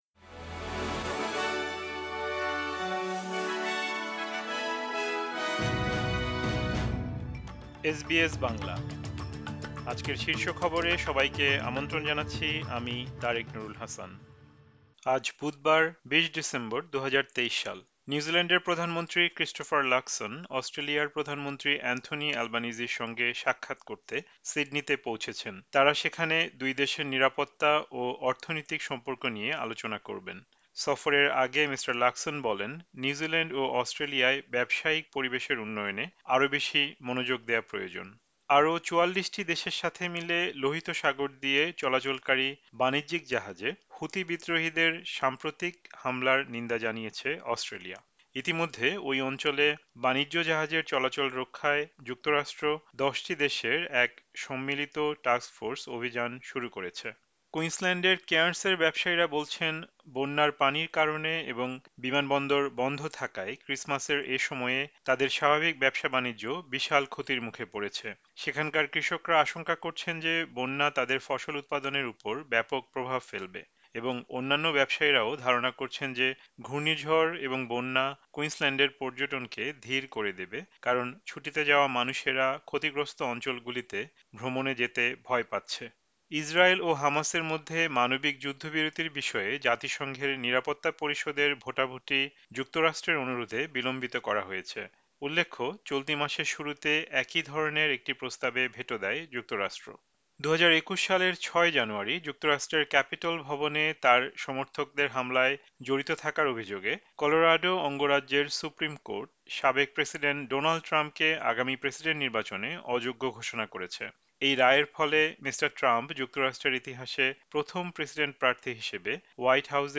এসবিএস বাংলা শীর্ষ খবর: ২০ ডিসেম্বর, ২০২৩